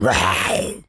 tas_devil_cartoon_10.wav